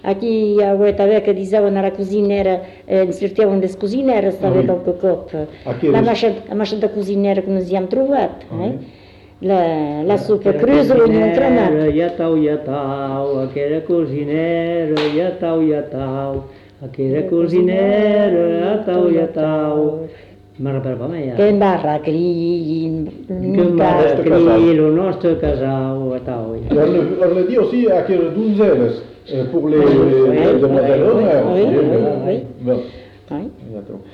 Aire culturelle : Bazadais
Lieu : Cazalis
Genre : chant
Effectif : 1
Type de voix : voix d'homme
Production du son : chanté